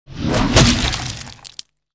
dragonclaw.wav